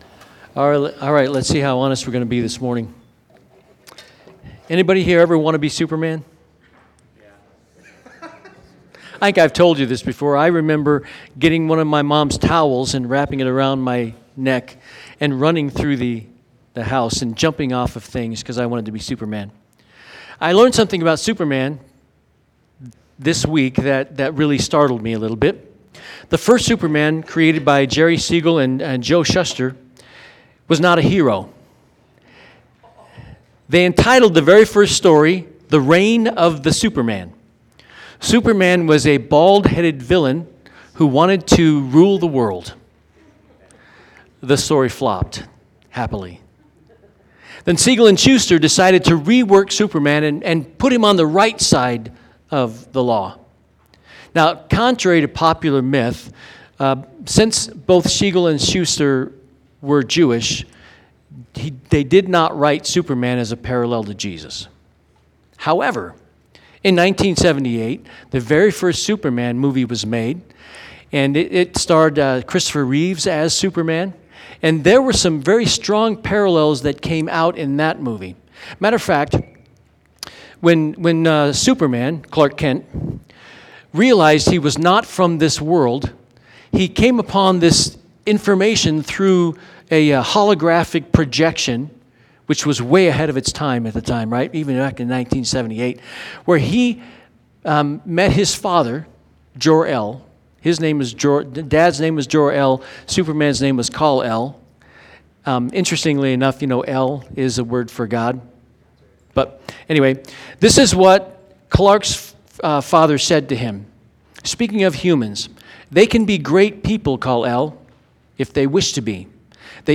Sermons | First Baptist Church of Golden